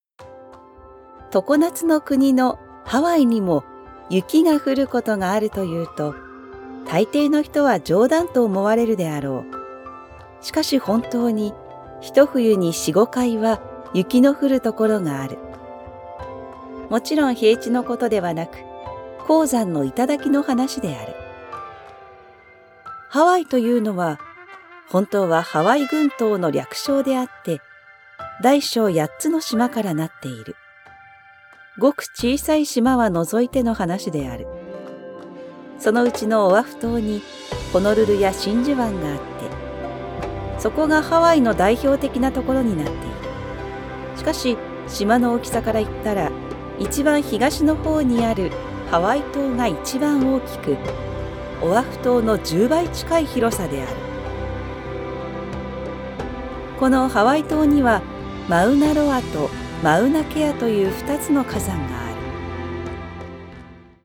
Natürlich, Vielseitig, Zuverlässig, Warm, Sanft
Audioguide
Her voice is known for its empathetic and believable quality, making it ideal for connecting with audiences on a deeper level.